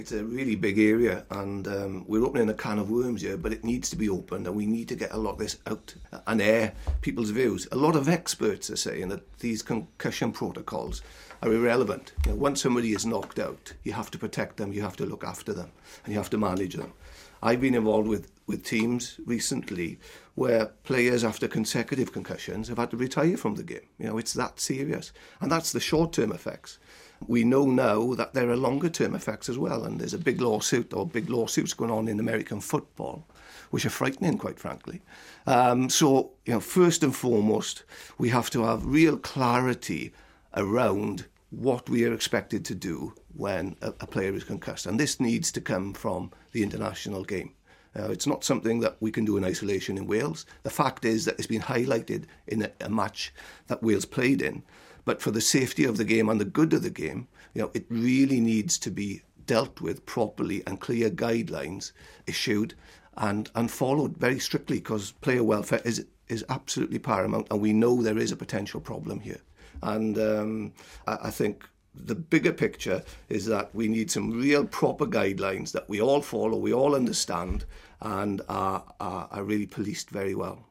Ex Wales centre Nigel Davies talks the dangers of concussion in rugby